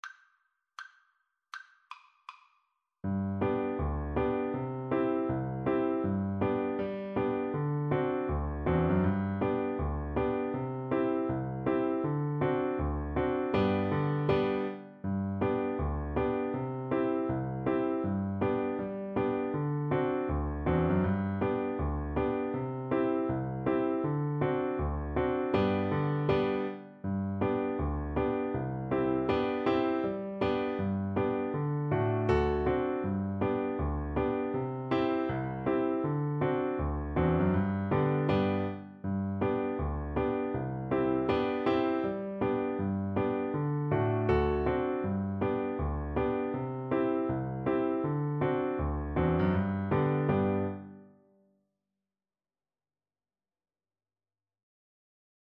G major (Sounding Pitch) (View more G major Music for Violin )
With a Swing = c. 80
2/2 (View more 2/2 Music)
Traditional (View more Traditional Violin Music)